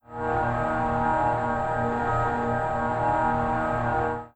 Samsung Galaxy S70 Startup.wav